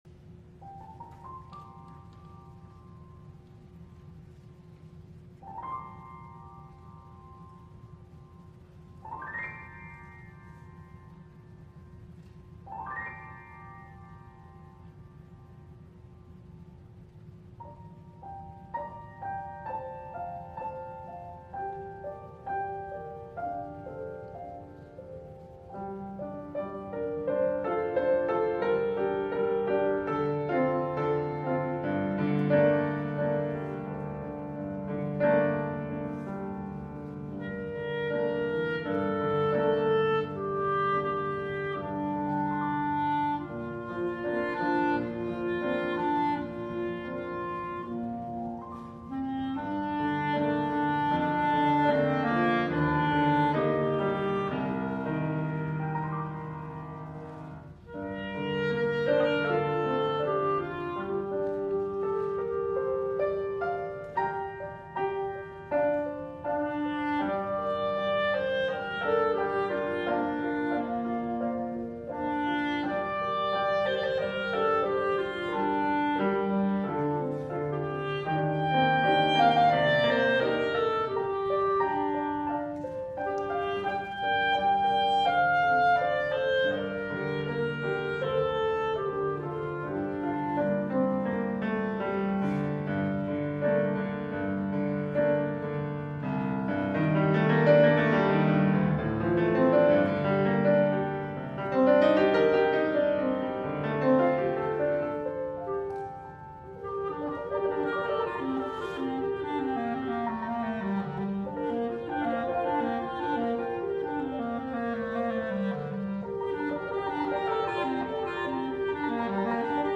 for Clarinet and Piano (2005)